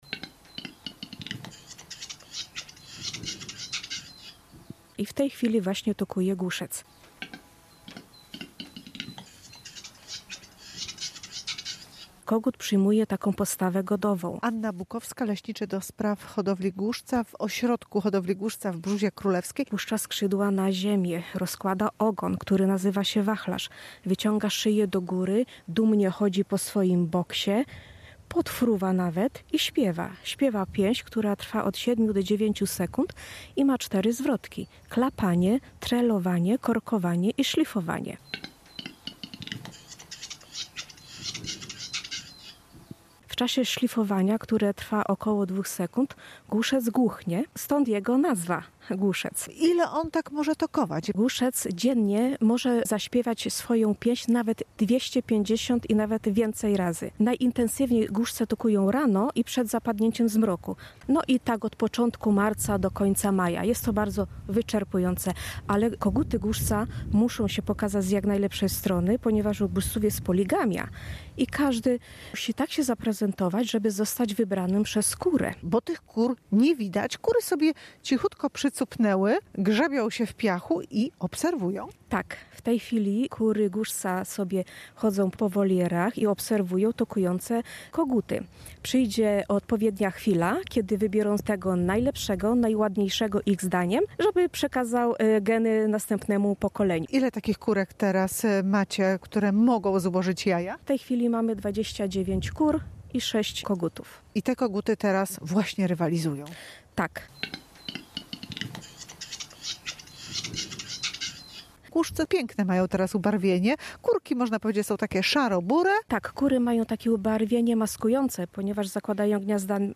Relacje reporterskie • W ośrodku hodowli głuszca w Brzózie Królewskiej trwa okres toków.
Śpiew głuszca trwa od 7 do 9 sekund i składa się z czterech części: klapania, trelowania, korkowania i szlifowania.
toki-gluszcow.mp3